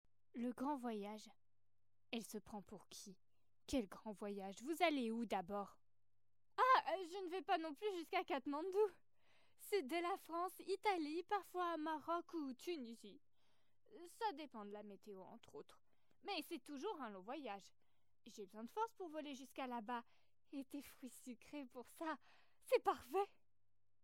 Extraits voix cinéma demo fictive